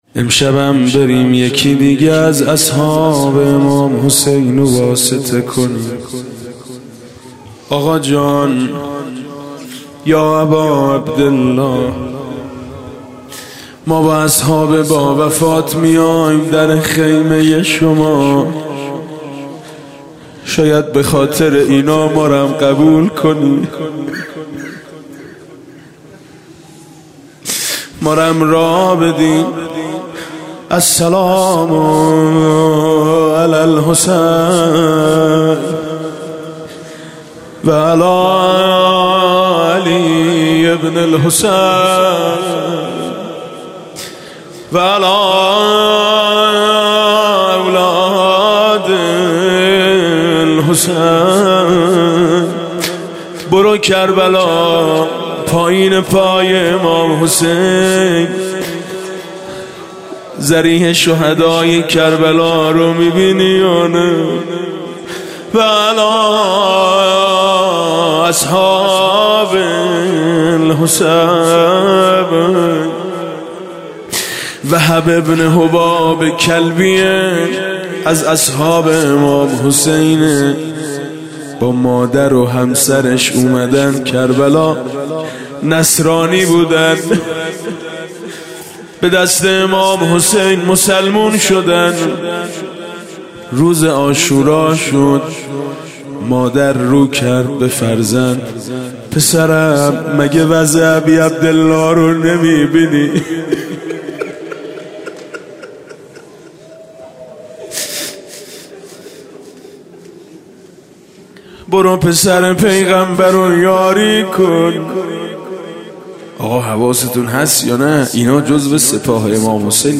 دانلود مناجات شب چهارم ماه رمضان الکریم ۱۳۹۷با نوای حاج میثم مطیعی – مجله نودیها